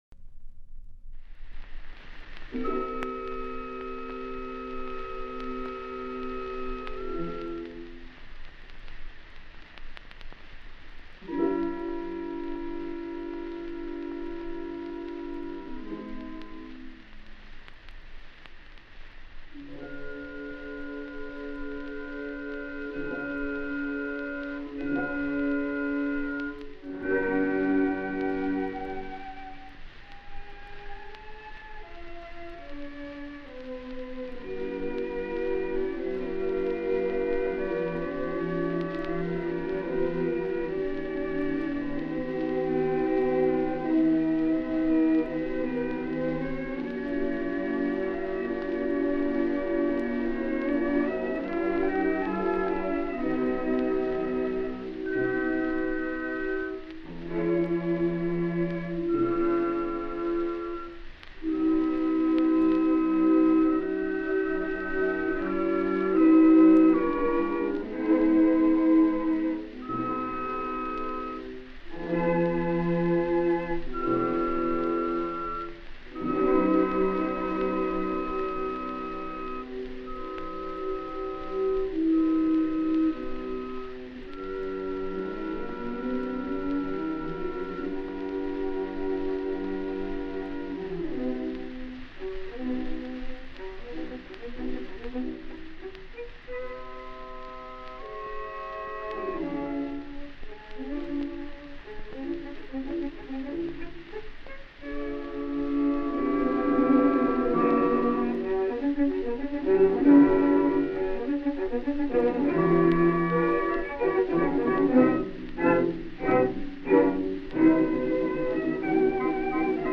Charm is not a word that generally zooms to the top of the chart when one recalls the conducting of Otto Klemperer, but his Beethoven First from Berlin 1924 — still made with the acoustical as opposed to electrical process – is so gemuetlich it seems to spring from a sidewalk café table more than a concert stage.
Klemperer capitalizes on this questioning mode in a sweet, intimate and recurrently “lost” introduction, moving thence into an allegro con brio which is lively for sure but takes its time from the start, only to indulge, organically, in supreme dawdles at the beginning of the second subject, very dainty and quite coquettish in this instance, and again a little later on when, bar 77, the basses legato their way to the depths in a curling pianissimo, making way for a statement from the oboe which even on the stark page of a printed score seems long-breathed indeed.